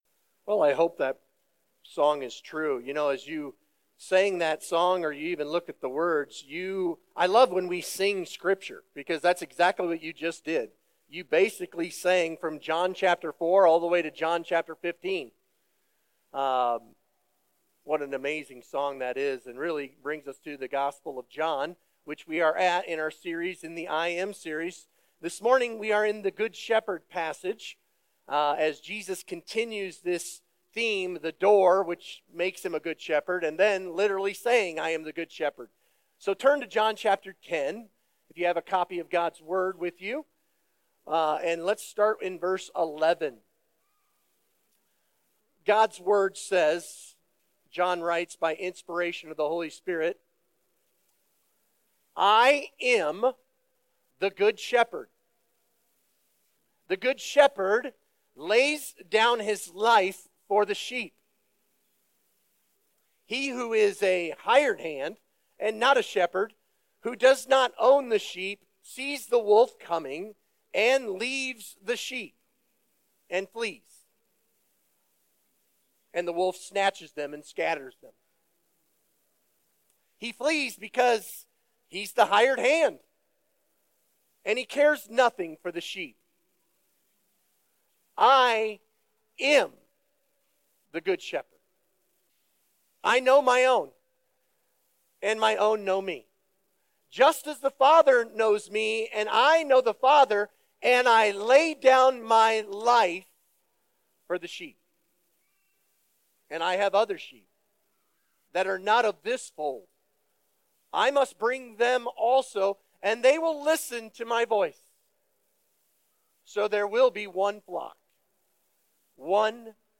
Sermon Questions Read John 10:11-21 & Ezekiel 34:1-16.